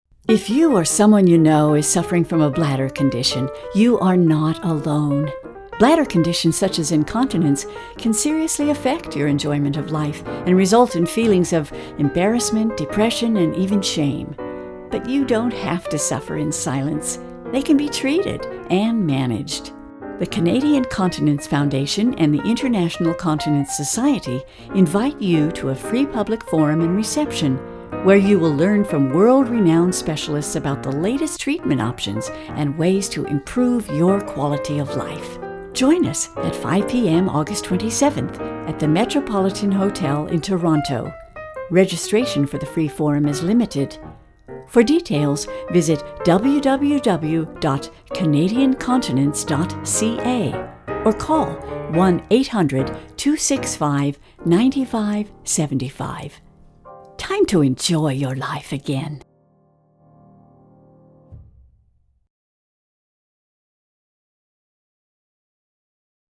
Promo
incontinence-with-music-3.mp3